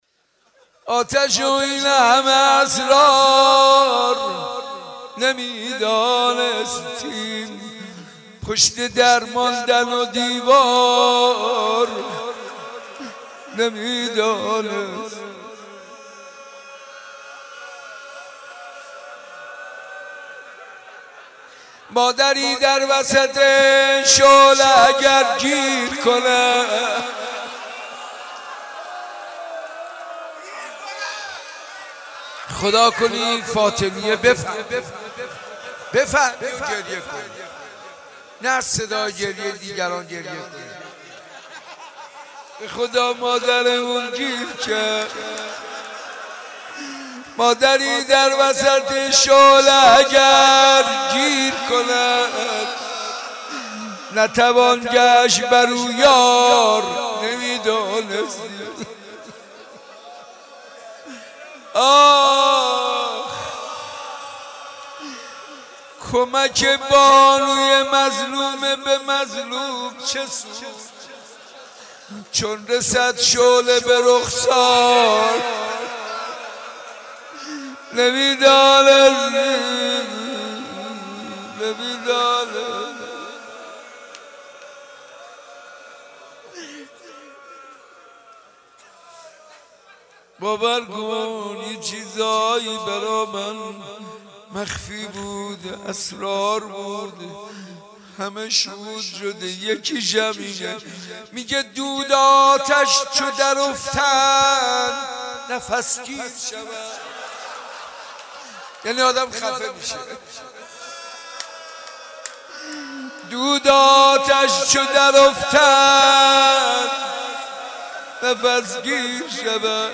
گلچین صوتی مراسم: